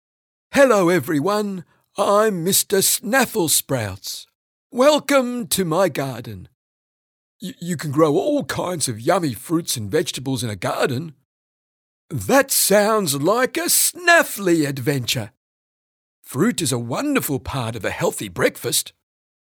Male
English (Australian)
Adult (30-50), Older Sound (50+)
Character / Cartoon
Children'S Cartoon Character
0804Mr_Snaffle_sprouts_childrens_character.mp3